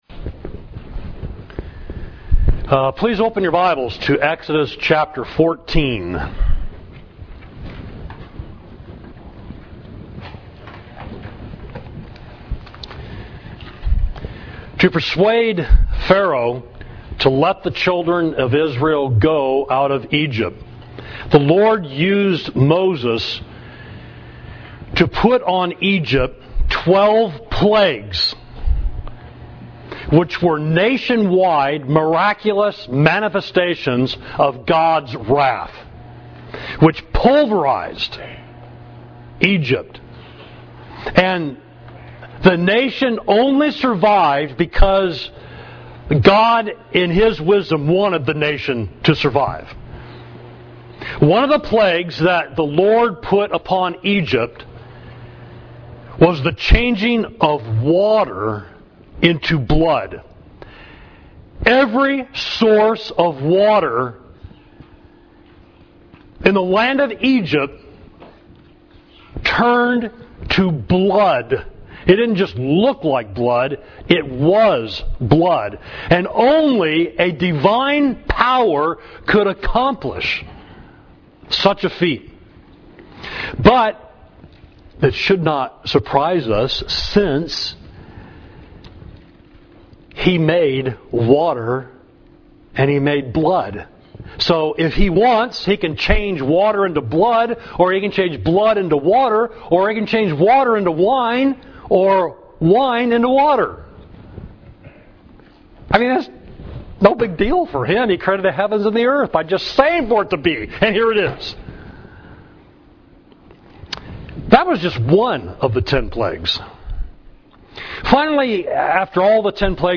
Sermon: Jehovah-Rapha: The Lord Heals You, Exodus 15.22–26 – Savage Street Church of Christ